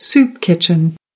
18. soup kitchen (n) /sup ˈkɪʧən/ Súp gà